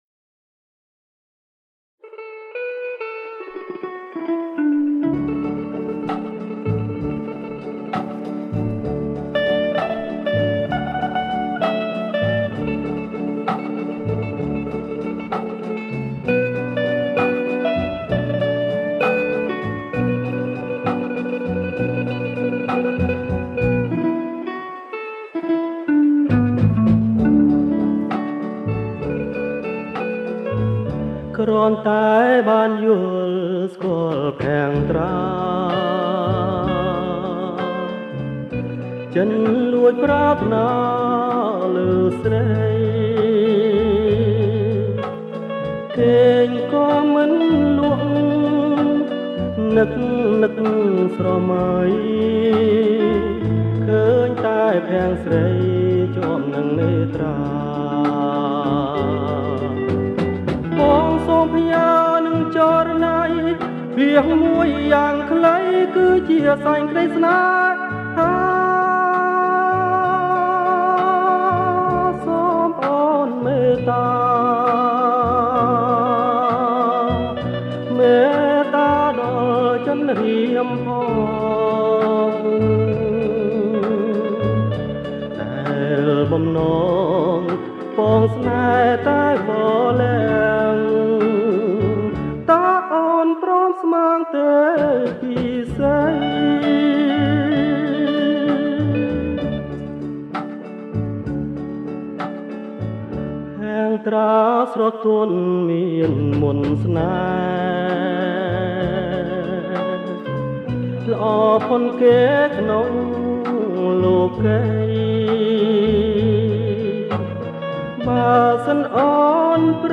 • បទភ្លេង បរទេស
• ប្រគំជាចង្វាក់ Slow Rock